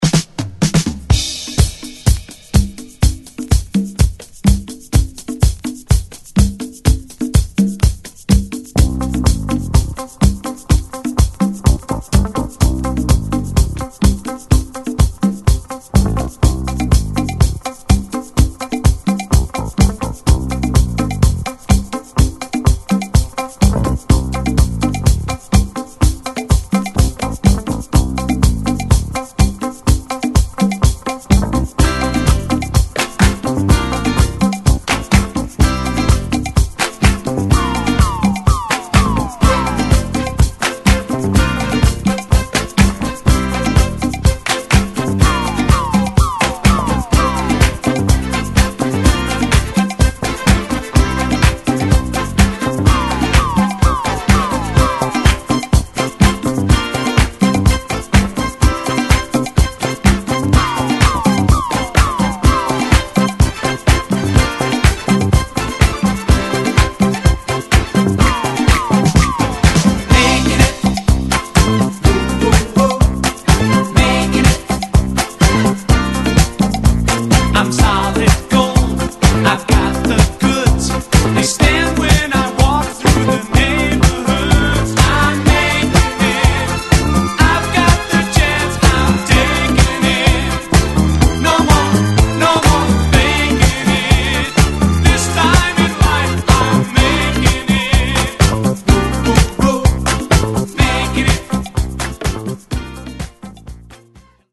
・ DISCO 70's 12'
頭のDisco Breakも◎な軽快&キャッチーDisco!!